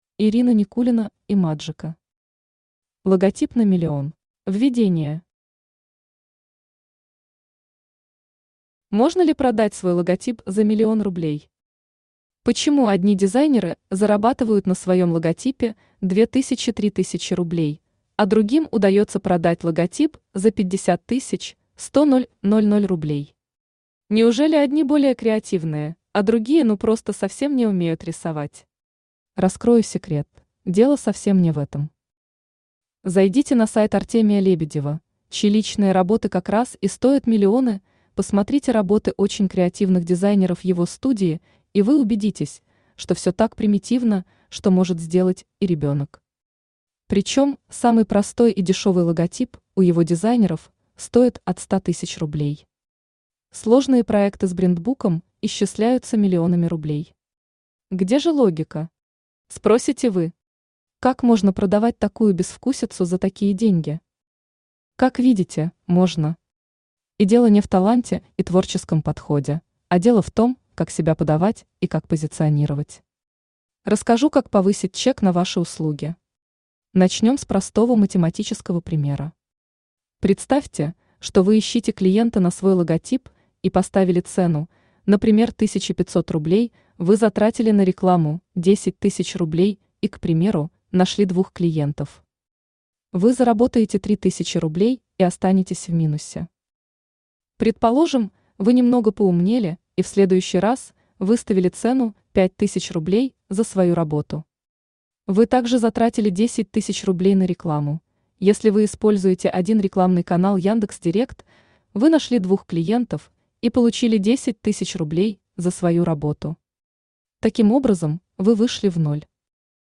Аудиокнига Логотип на миллион | Библиотека аудиокниг
Aудиокнига Логотип на миллион Автор Ирина Никулина Имаджика Читает аудиокнигу Авточтец ЛитРес.